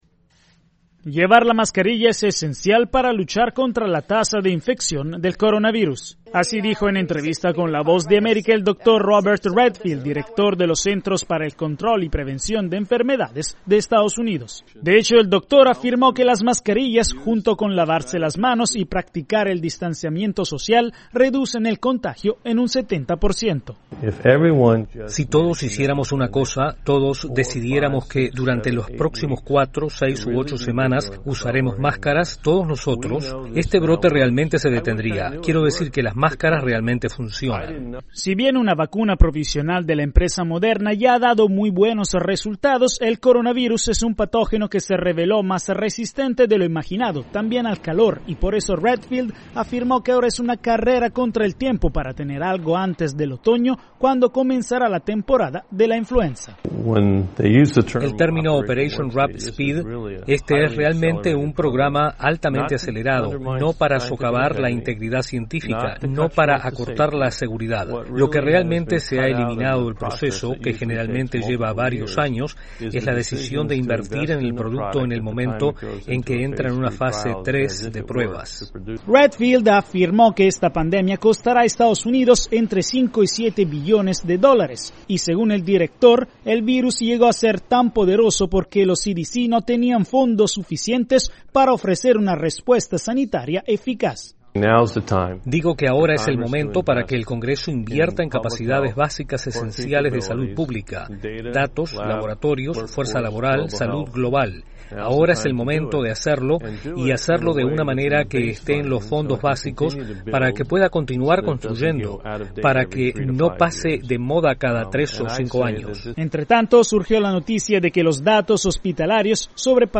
Frente al coronavirus, los centros de control y prevención de enfermedades en Estados Unidos asumieron un papel crucial. Su director, Robert Redfield, en entrevista con la Voz de América, habló de las herramientas para luchar contra el patógeno, desde las mascarillas hasta una posible vacuna.